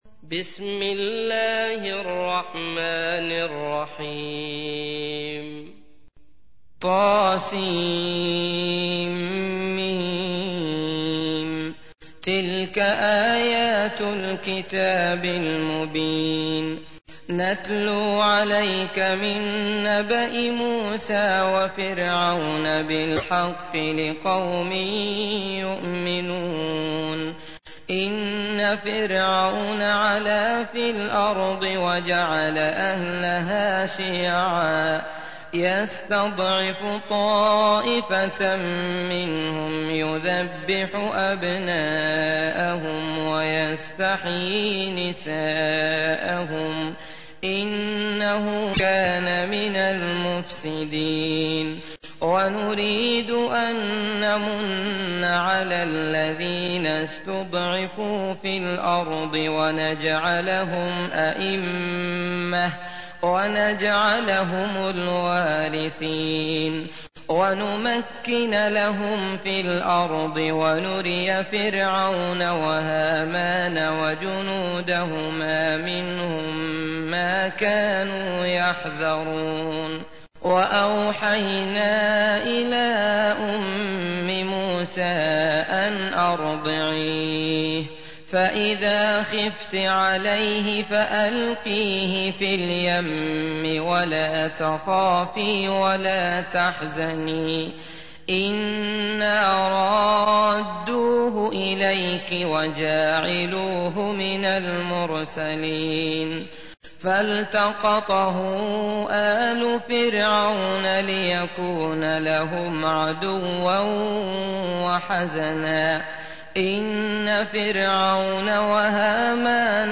تحميل : 28. سورة القصص / القارئ عبد الله المطرود / القرآن الكريم / موقع يا حسين